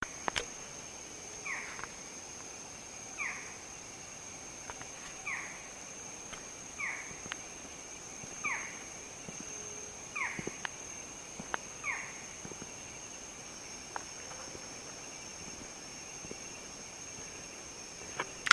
Barred Forest Falcon (Micrastur ruficollis)
Life Stage: Adult
Location or protected area: Parque Provincial Cruce Caballero
Condition: Wild
Certainty: Observed, Recorded vocal